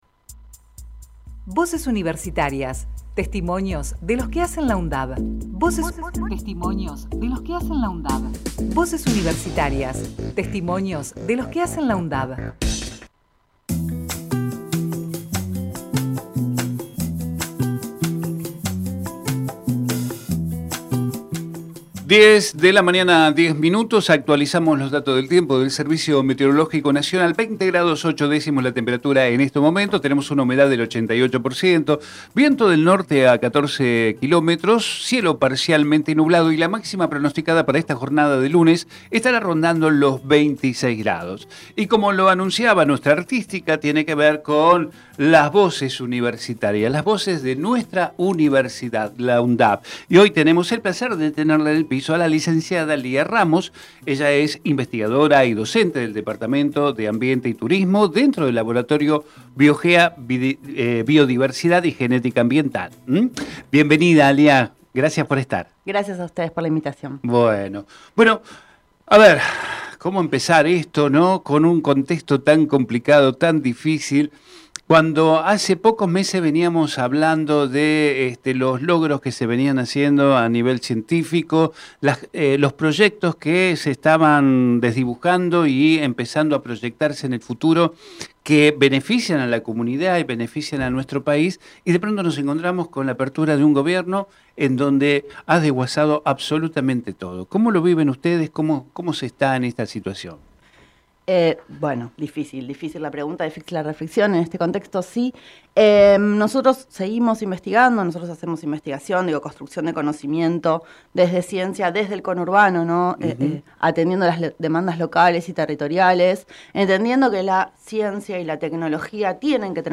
Compartimos la entrevista realizada en Territorio Sur